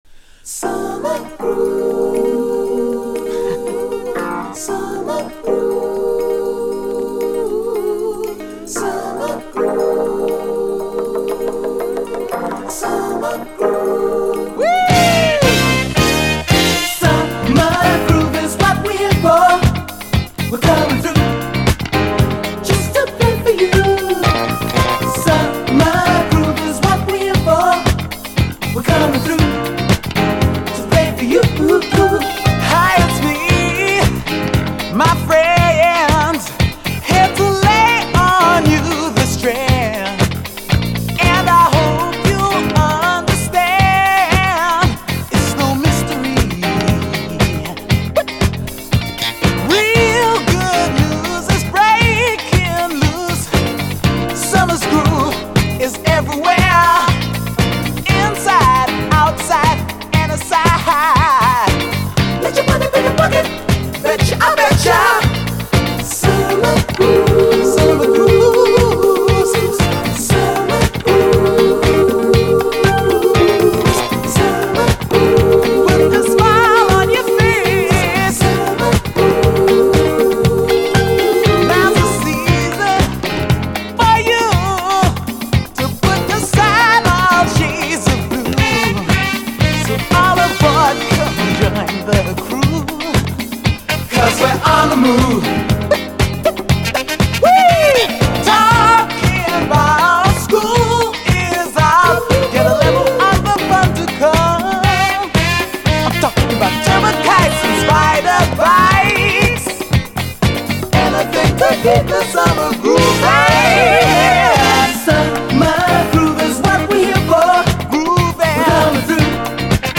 SOUL, 70's～ SOUL, DISCO
試聴ファイルはこの盤からの録音です
イントロの激気持ちいいコーラスだけでヤバさを確信するはず！